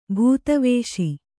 ♪ bhūtavēśi